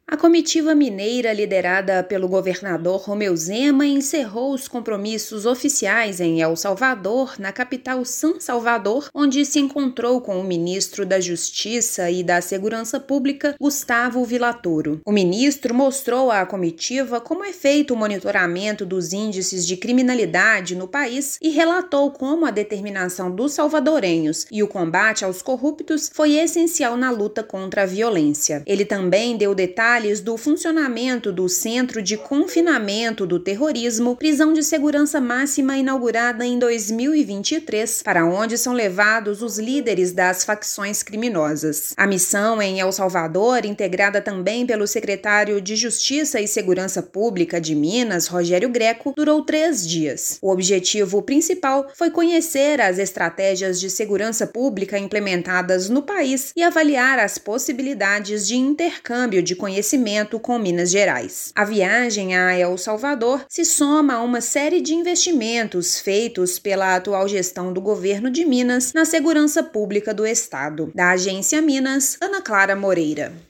Comitiva conheceu o monitoramento dos índices de criminalidade do país e mais detalhes sobre o sistema prisional local. Ouça matéria de rádio.